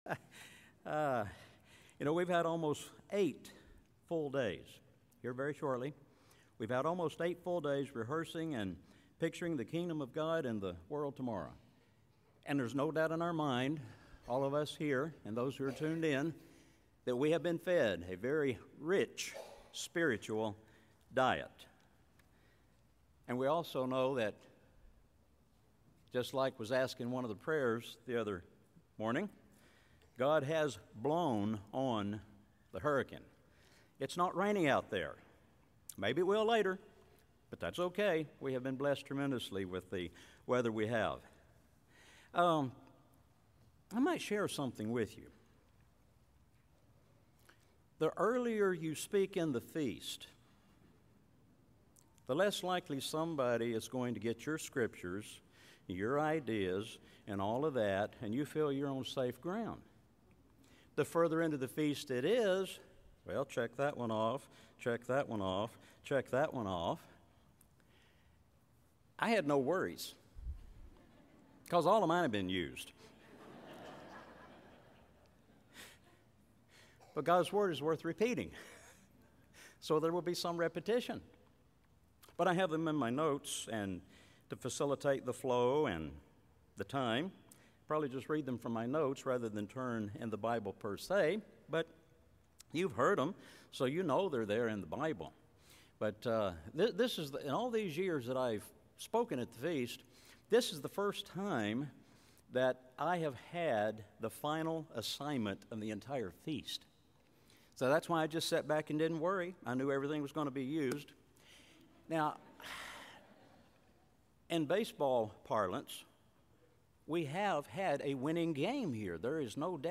This sermon was given at the Panama City Beach, Florida 2020 Feast site.